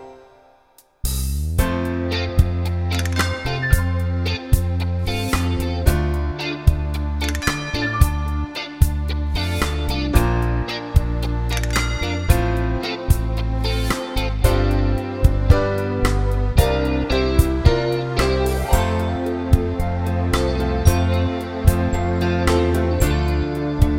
no Backing Vocals Soundtracks 4:12 Buy £1.50